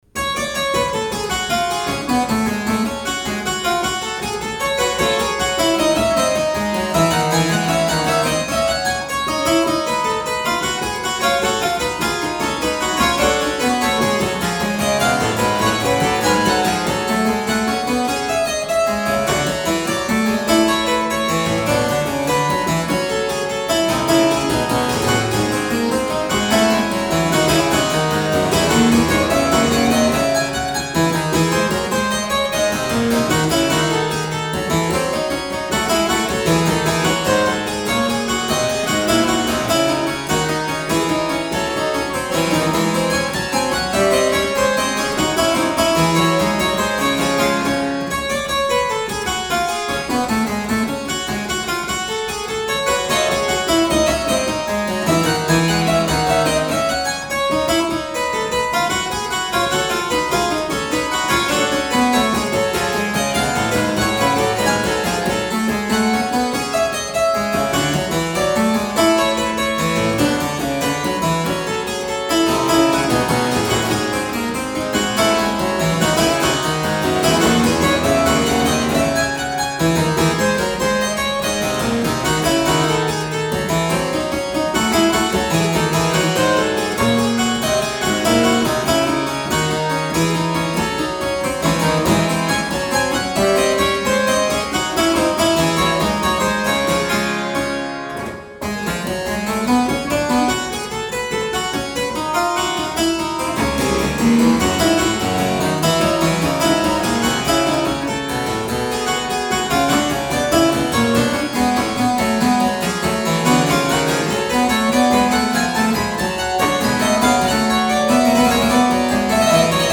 These are warts-and-all performances. Apart from *anything else*, the quilling on the Ruckers copy needs serious attention (even more than before).